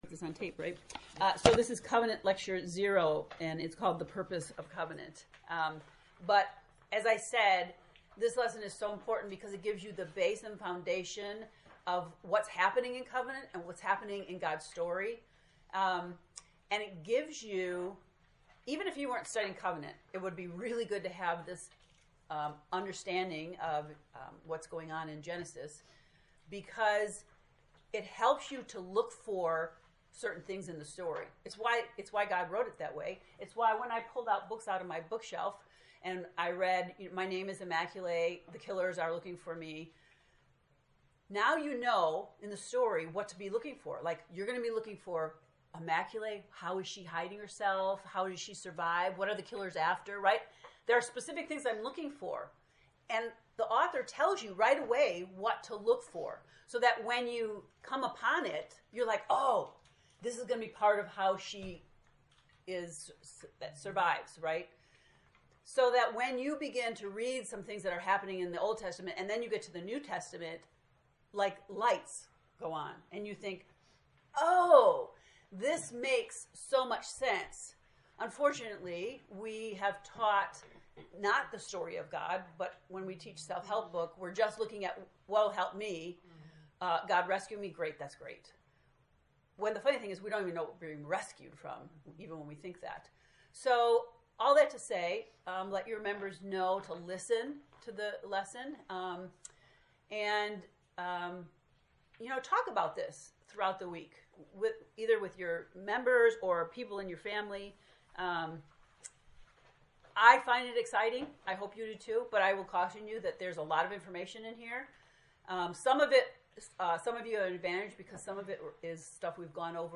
COVENANT lecture 0
To listen to the lecture 0 “The Purpose of Covenant,” click below: